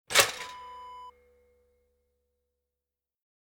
Toaster Lever Up Wav Sound Effect #1
Description: The sound of a toaster lever popping up
Properties: 48.000 kHz 16-bit Stereo
A beep sound is embedded in the audio preview file but it is not present in the high resolution downloadable wav file.
Keywords: toaster, lever, toast, bread, pop, popping, up
toaster-lever-up-preview-1.mp3